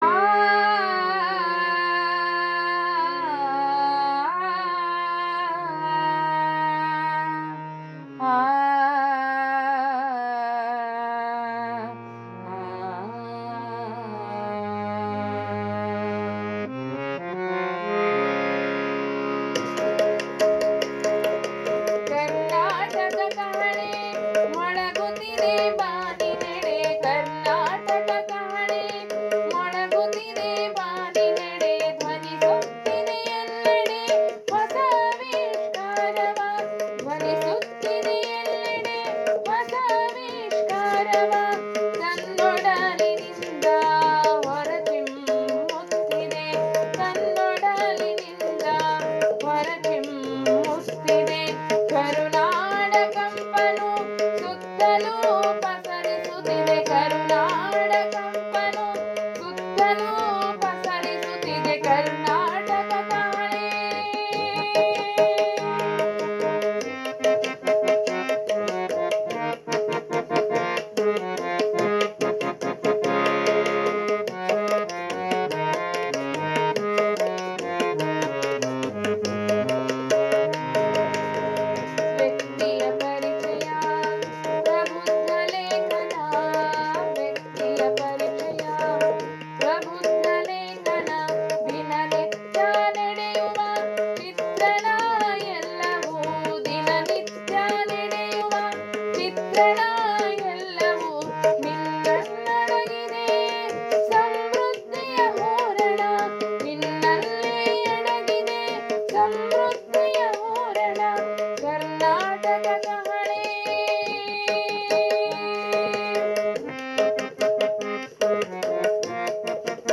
ರಾಗ ಸಂಯೋಜಿಸಿ‌ ಹಾಡಿದ್ದಾರೆ